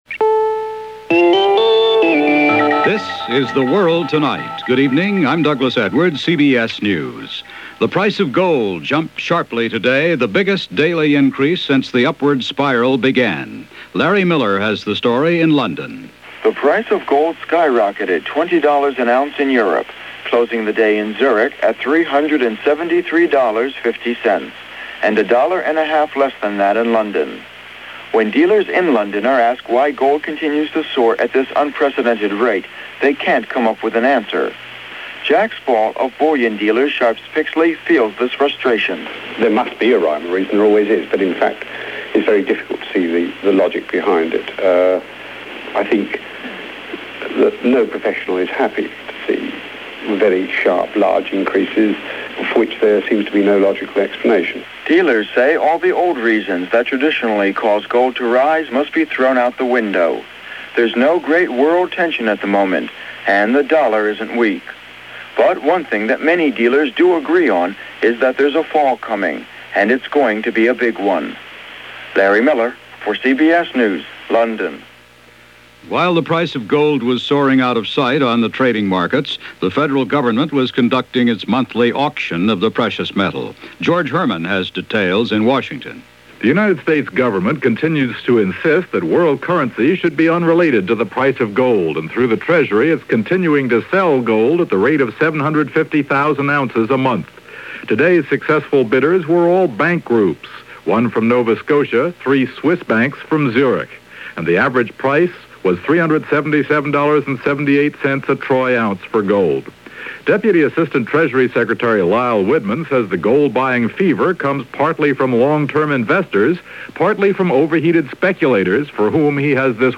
And that’s a small portion of what went on this September 18th in 1979 as reported over The World Tonight from CBS Radio.